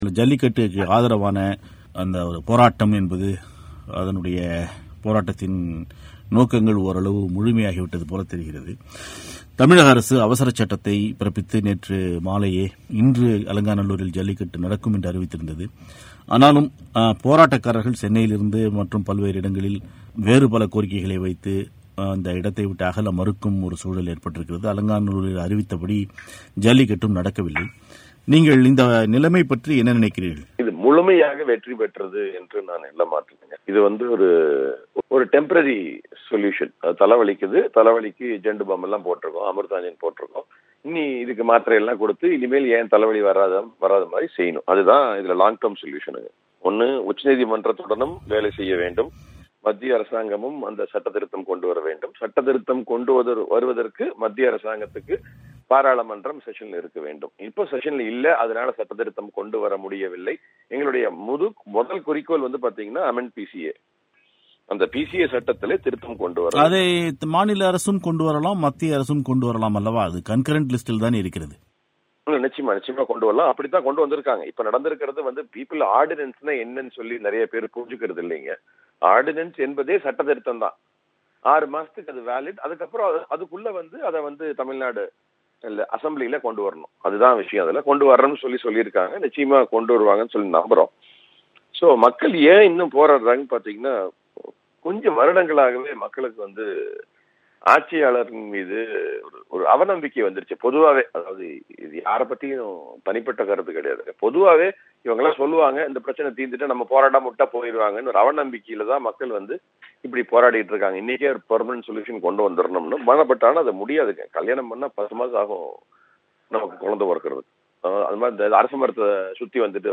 பிபிசி தமிழோசைக்கு பேட்டியளித்த